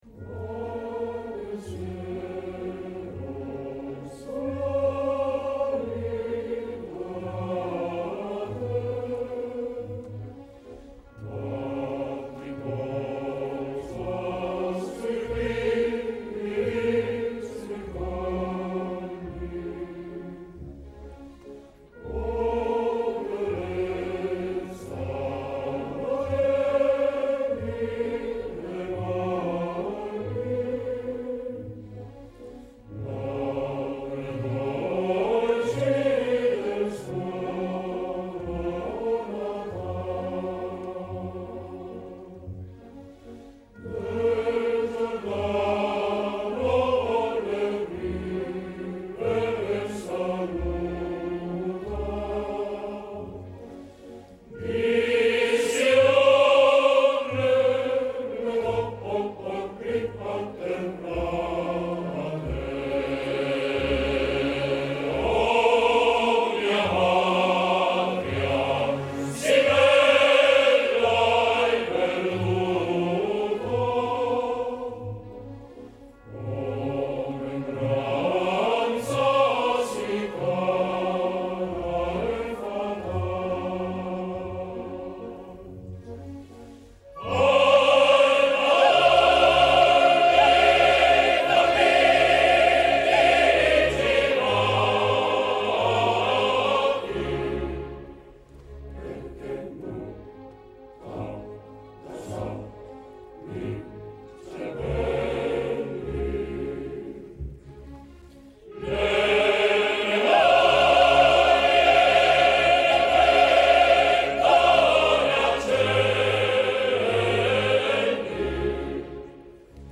Written by Temistocle Solera
Music by Giuseppe Verdi
Performed by Orchestra e Coro Dell’Arena Di Verona